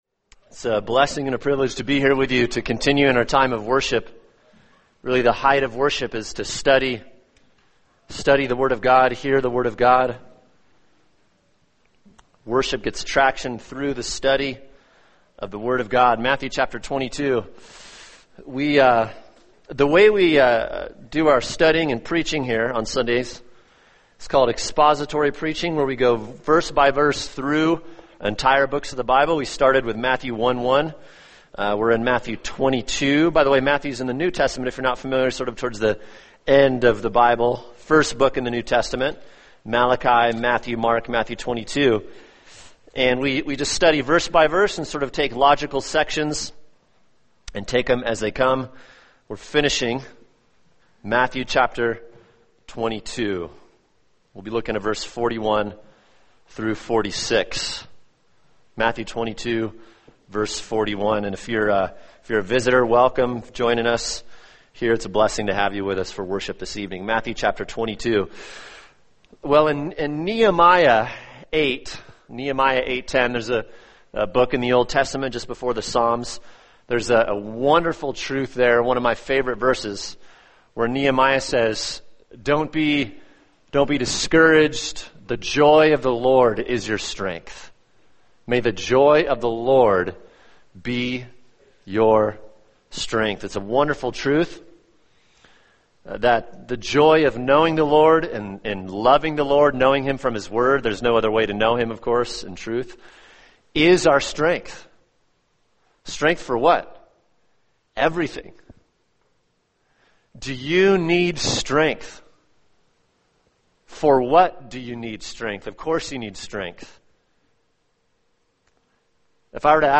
[sermon] Matthew 22:40-46 – The Supremacy of the Messiah | Cornerstone Church - Jackson Hole